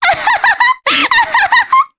Mediana-Vox_Happy3_jp.wav